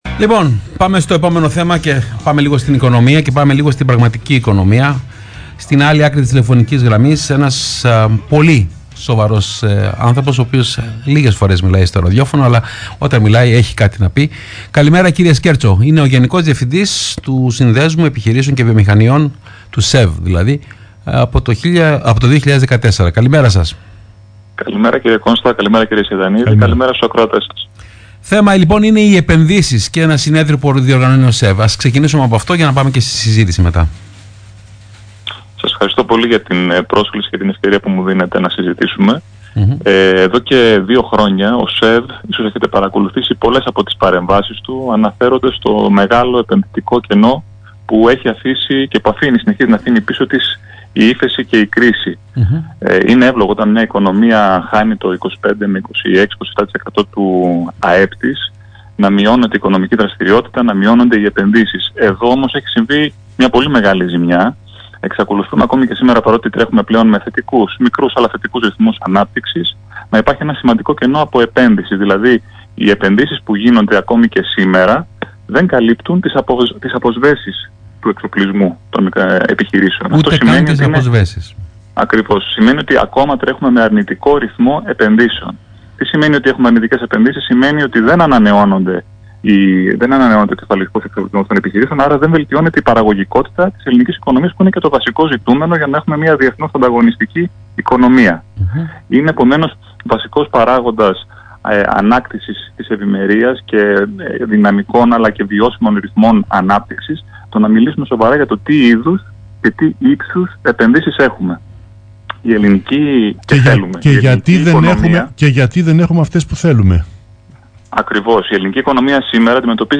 Συνέντευξη του Γενικού Διευθυντή του ΣΕΒ, κ. Άκη Σκέρτσου στον Ρ/Σ ΠΡΩΤΟ ΘΕΜΑ, 21/4/2018